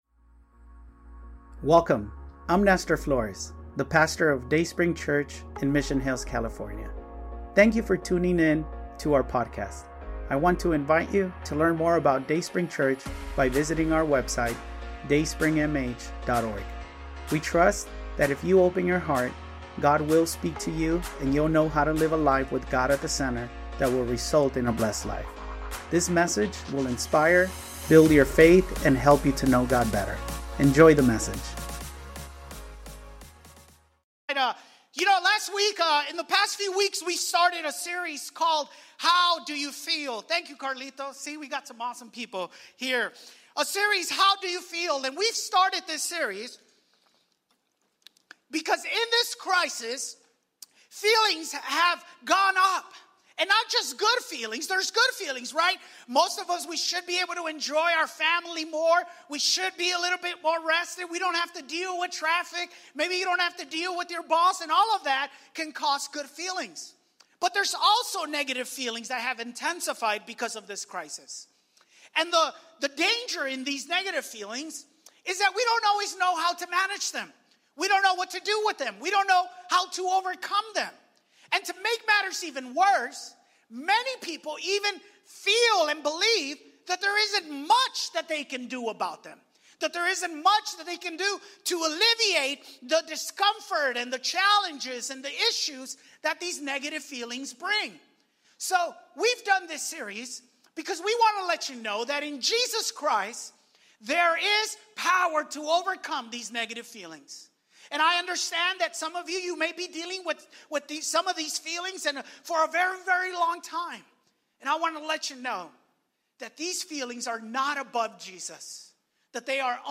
Wheater you are joining us online or in our sanctuary, we are glad to connect with you. Today we continue a series of messages where we are talking about what to do about the feelings we deal with.